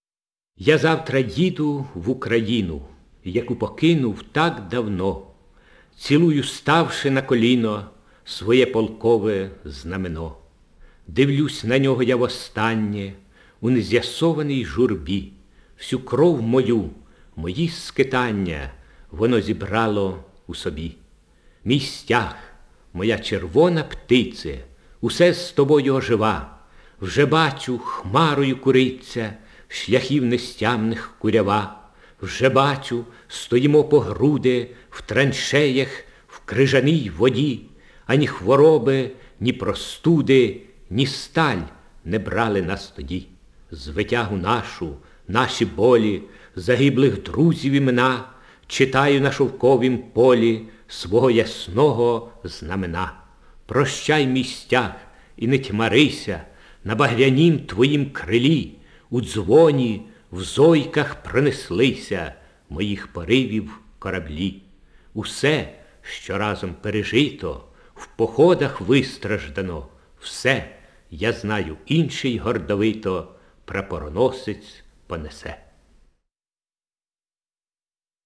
Index of /storage/Oles_Gonchar/Записи голосу Гончара